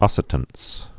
(ŏsĭ-təns)